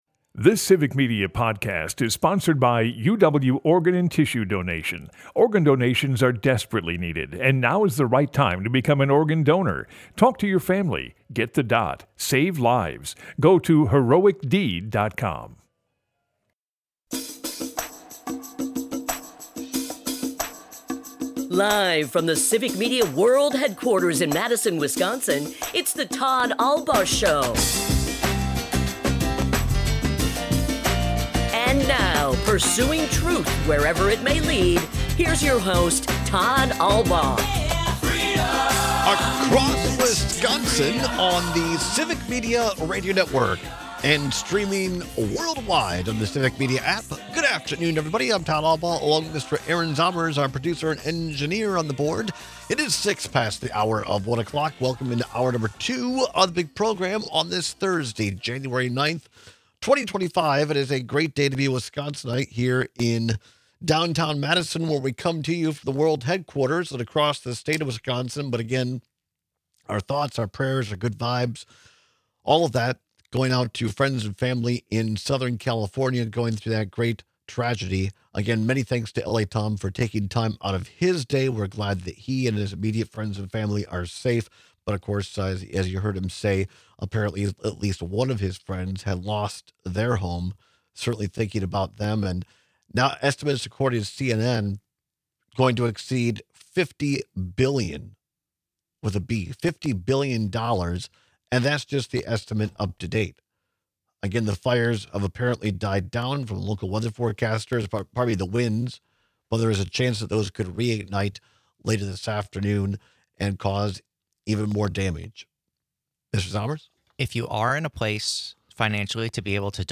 We take your calls and texts!